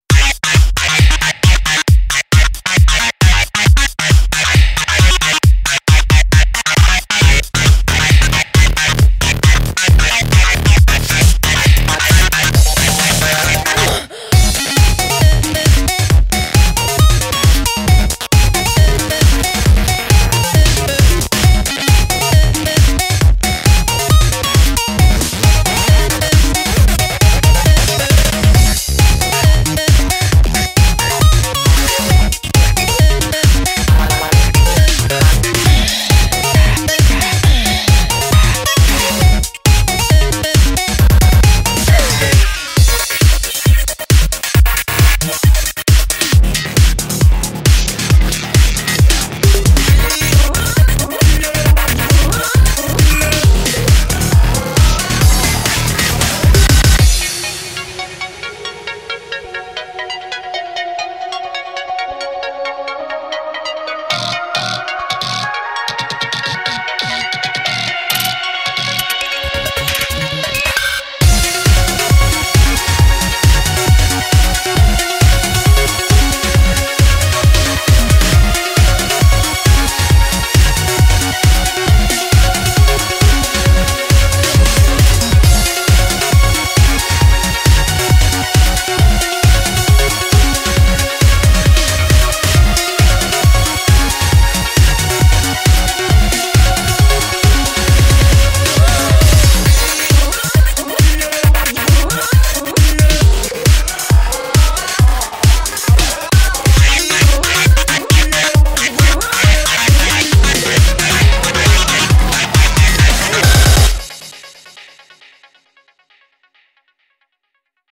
BPM135
Audio QualityPerfect (High Quality)
Comentarios[ELECTRO]